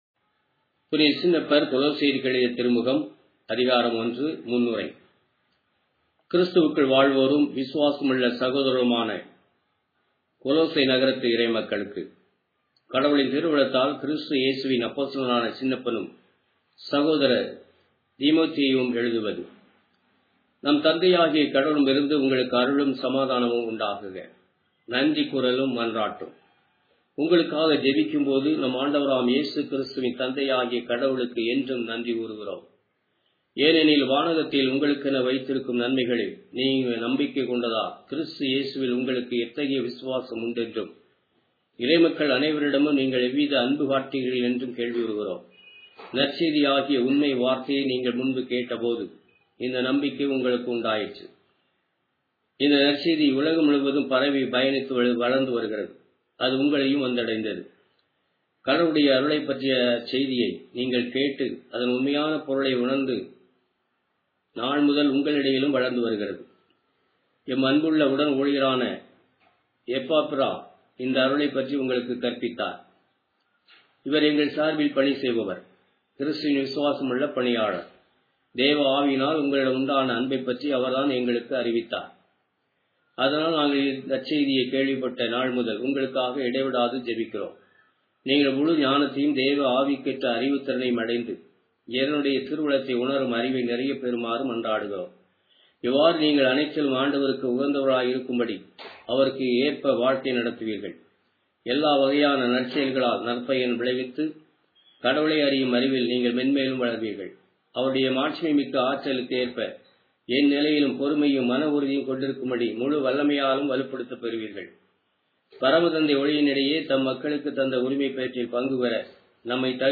Tamil Audio Bible - Colossians 1 in Rcta bible version